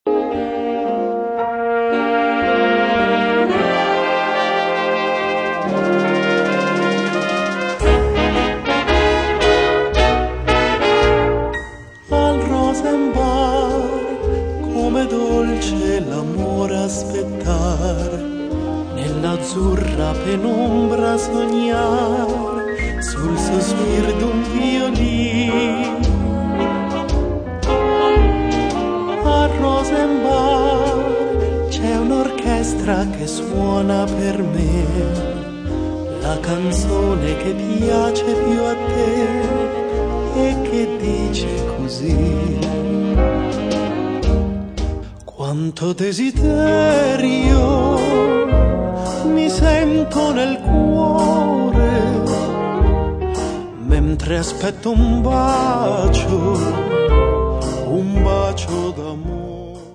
pianoforte
sax contralto, clarinetto
sax tenore
tromba
trombone
chitarra
contrabbasso
batteria